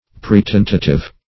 pretentative - definition of pretentative - synonyms, pronunciation, spelling from Free Dictionary
Search Result for " pretentative" : The Collaborative International Dictionary of English v.0.48: Pretentative \Pre*ten"ta*tive\, a. [Pref. pre- + tentative: cf. L. praetentare to try beforehand.]